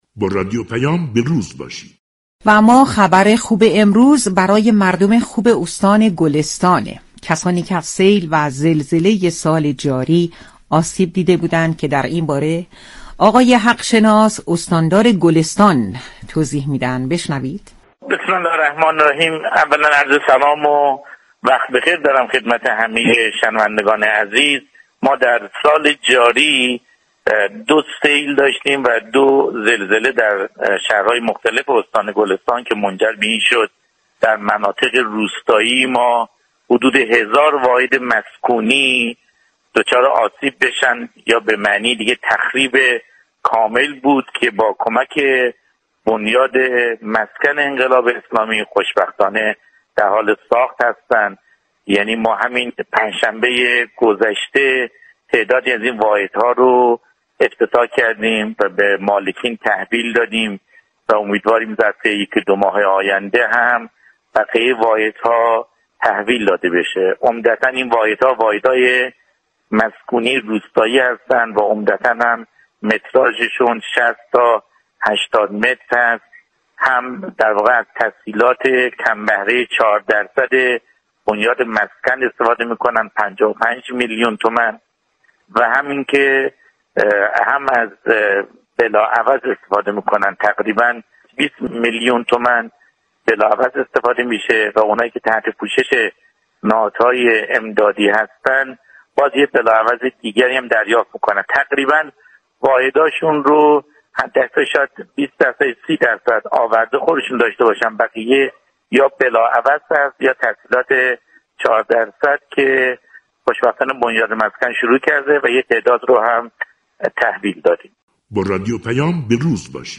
حق شناس ، استاندار گلستان در گفتگو با رادیو پیام ، جزئیاتی از آخرین وضعیت بازسازی مناطق سیل‌زده استان گلستان را بازگو كرد .